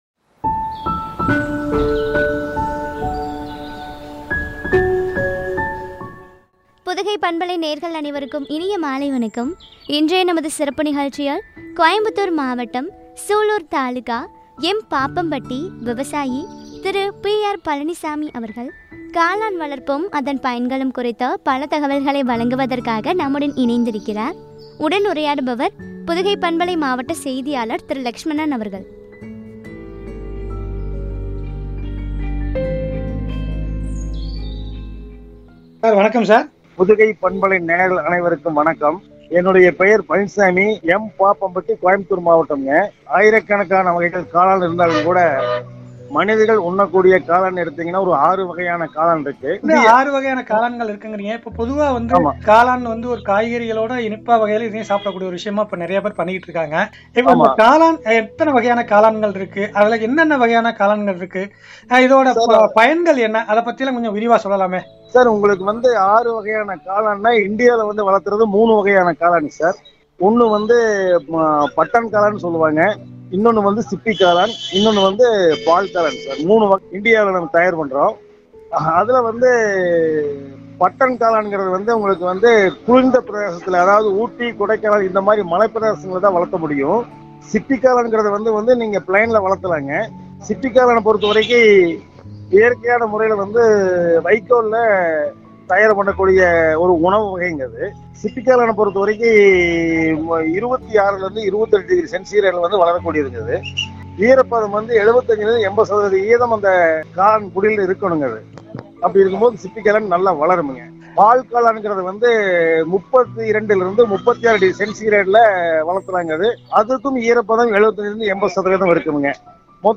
காளான் வளர்ப்பு, பயன்களும் பற்றிய உரையாடல்.